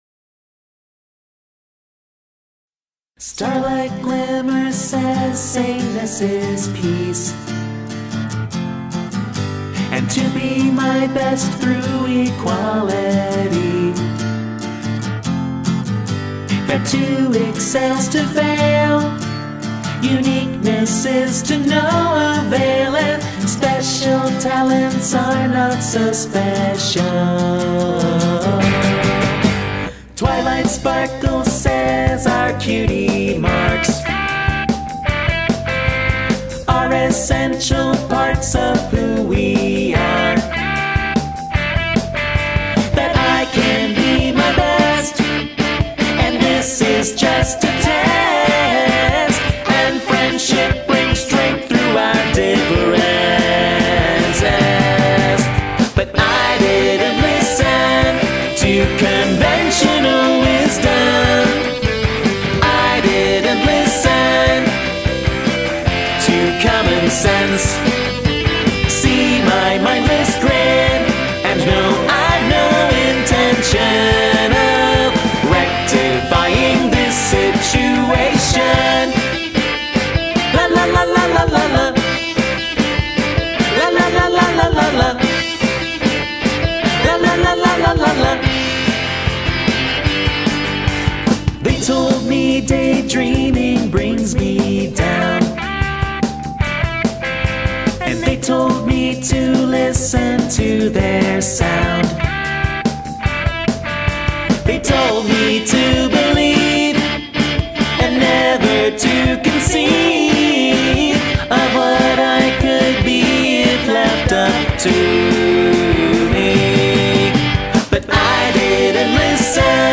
power-pop band